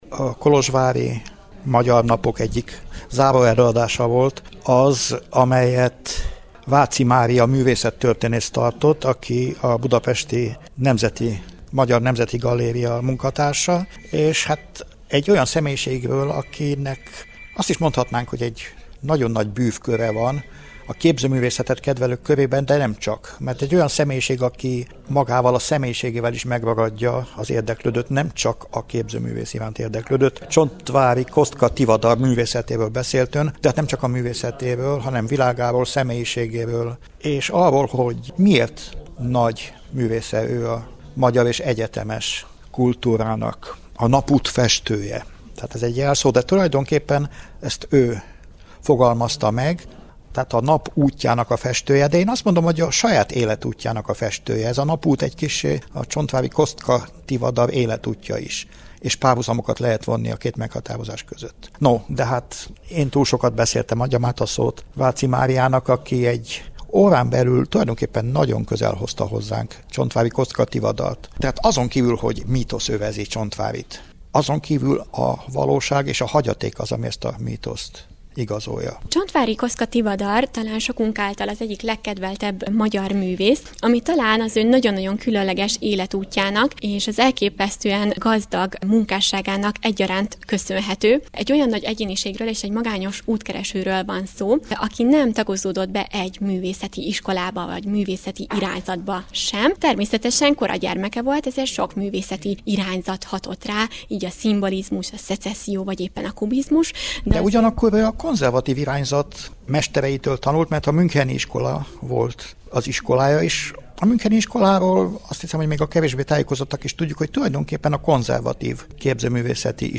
Az eseményt követően készült interjú az előadóval.